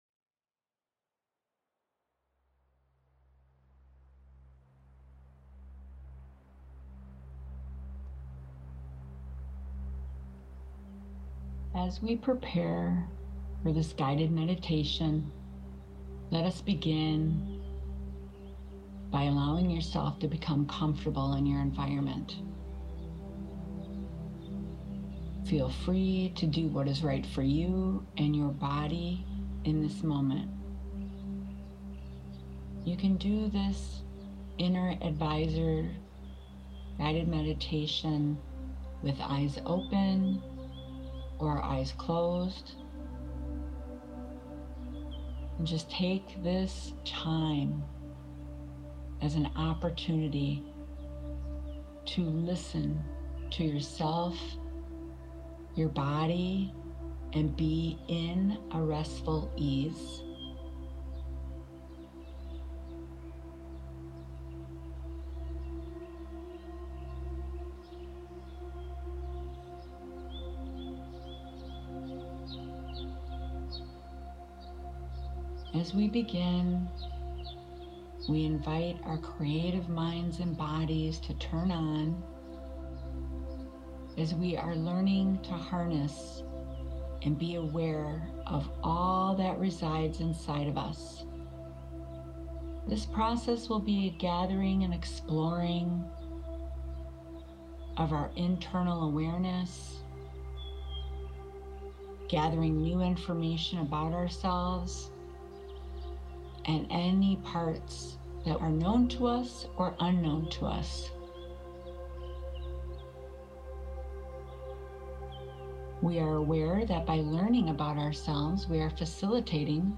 Download your bilateral visualization (53 MB)